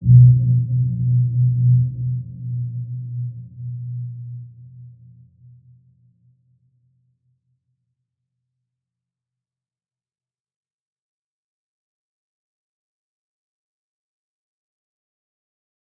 Dark-Soft-Impact-B2-p.wav